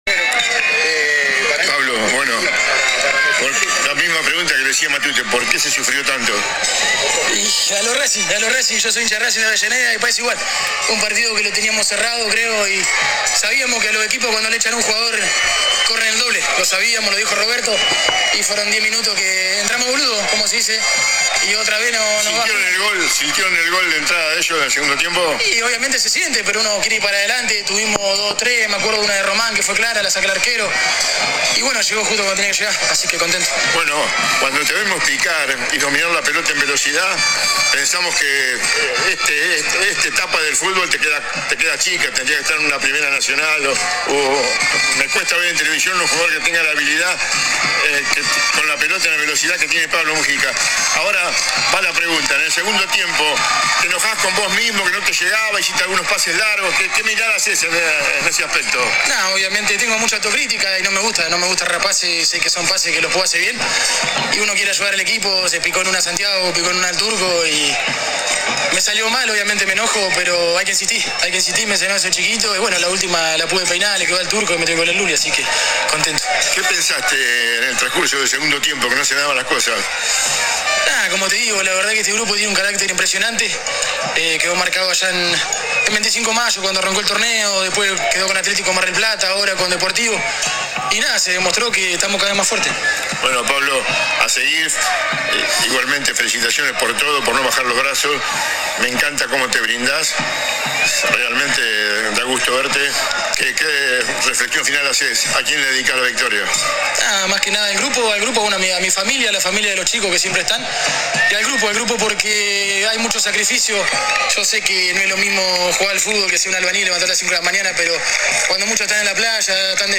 Dialogó para nuestro portal concluida la brega.
AUDIO DE LA ENTREVISTA